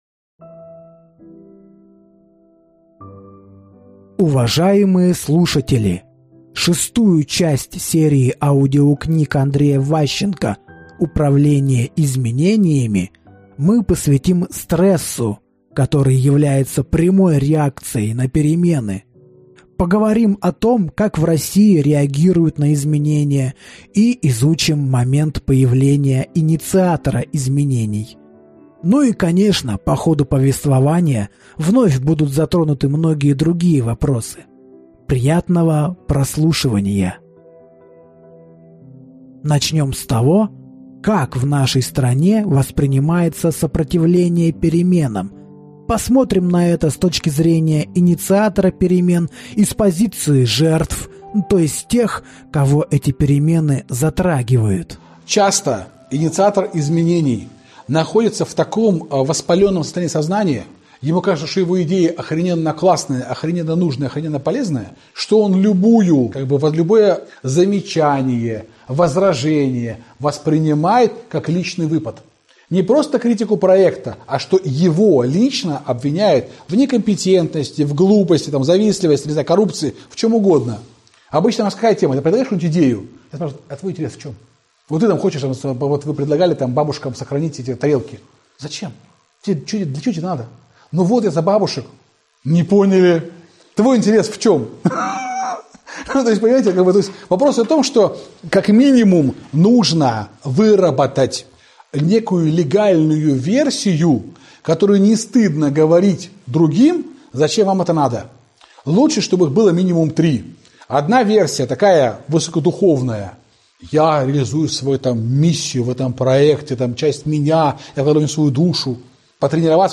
Аудиокнига Управление изменениями. Российская практика. Часть 6 | Библиотека аудиокниг